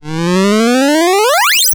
ChipTune Arcade FX 06.wav